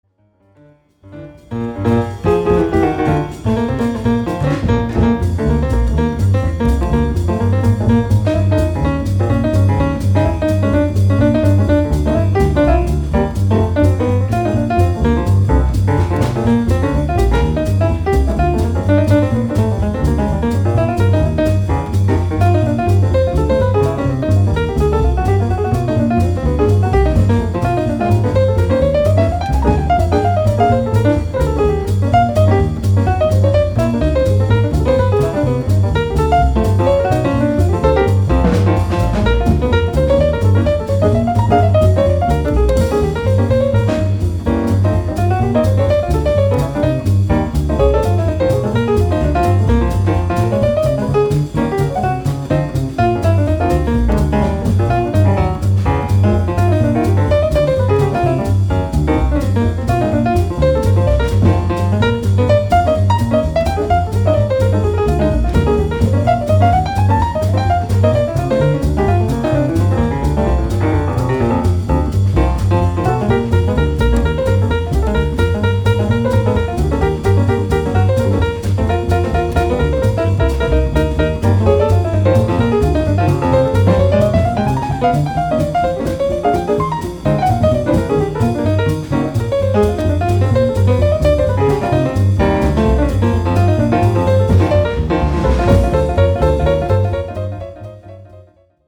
uptempo bop blues
piano and bass